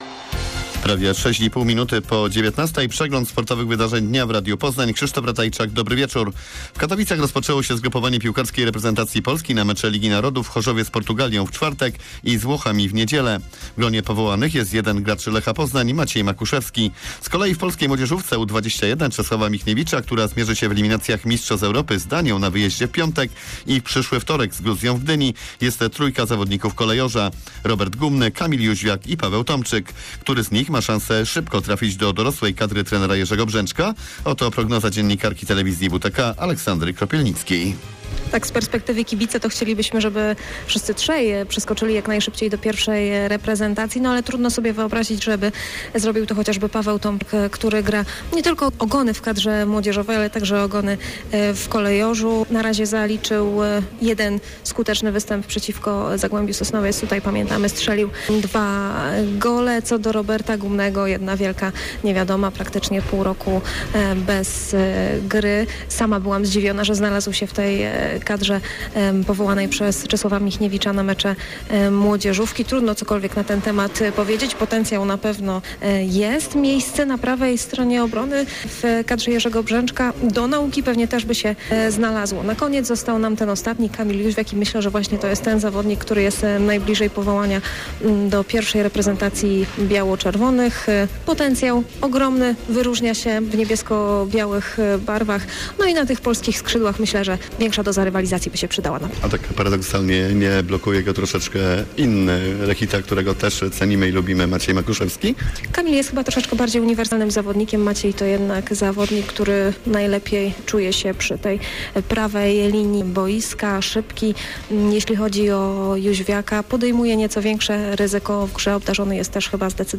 08.10. serwis sportowy godz. 19:05